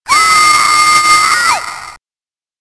a scream as it rushes towards the screen.
It's just to get you to jump.
splatterp2_scream.mp3